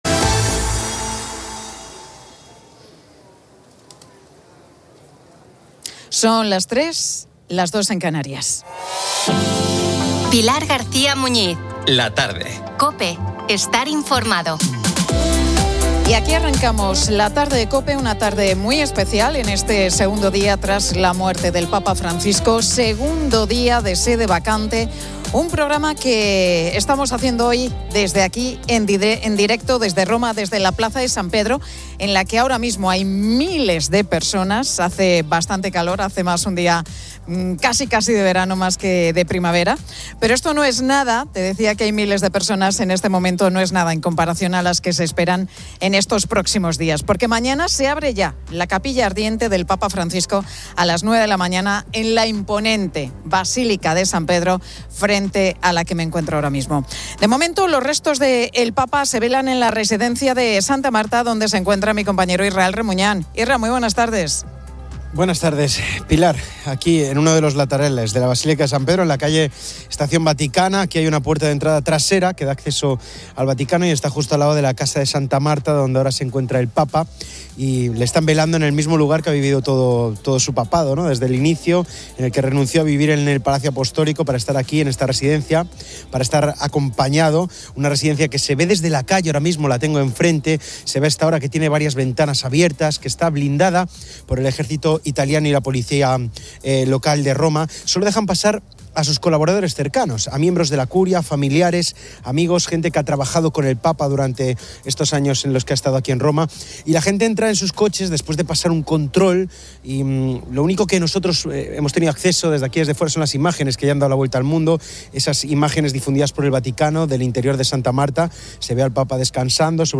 La Tarde 15:00H | 22 ABR 2025 | La Tarde Pilar García Muñiz y el equipo de La Tarde de COPE desde Roma por la muerte del Papa Francisco.